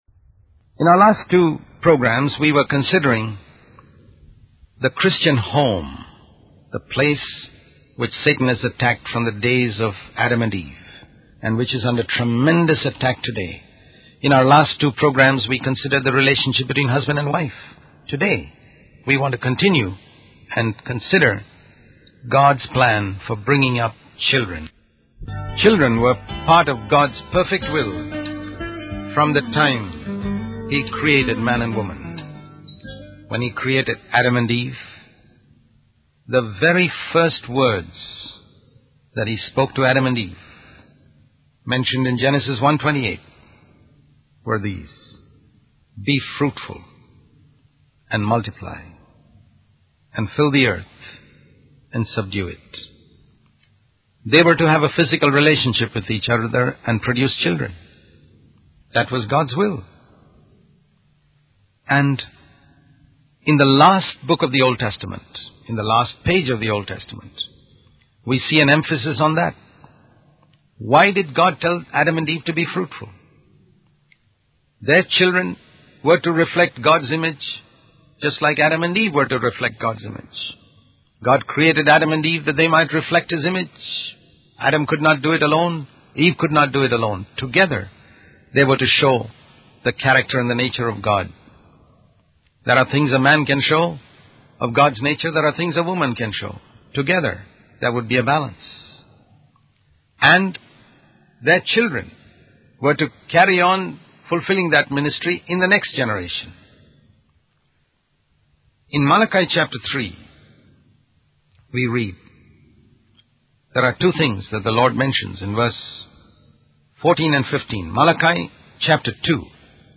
In this sermon, the speaker emphasizes the importance of teaching children to be honest and responsible. He uses the example of a child stealing a pencil and explains how ignoring such behavior can lead to more serious wrongdoing in the future.